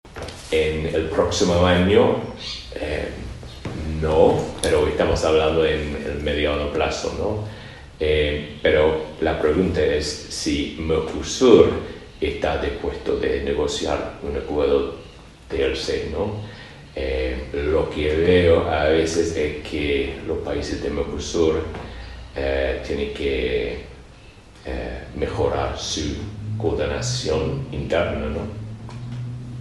Entrevistas
En diálogo con Índice 810, el embajador sostuvo sobre la salida del Reino Unido de la UE que se debe respetar lo que dijo la ciudadanía pero que más allá de la separación, está garantizado el trabajo conjunto con toda Europa.